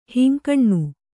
♪ hinkaṇṇu